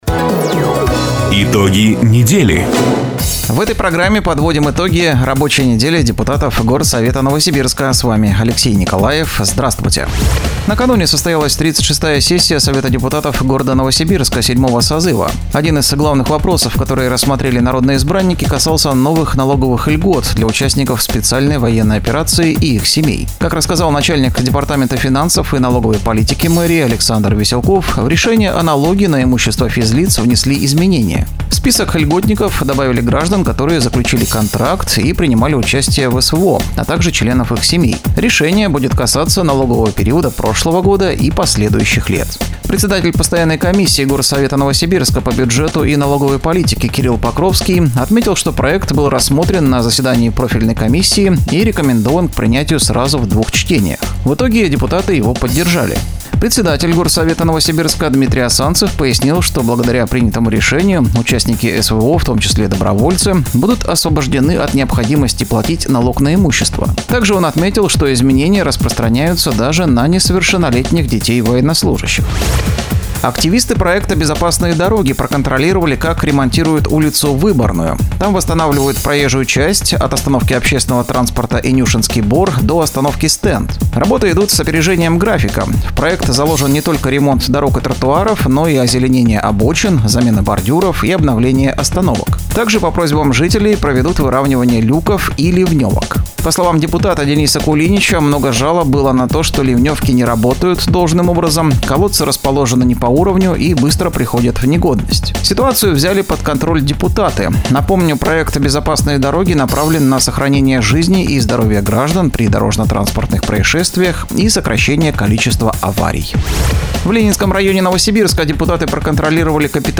Запись программы "Итоги недели", транслированной радио "Дача" 22 июня 2024 года.